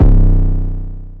[808] bad mood.wav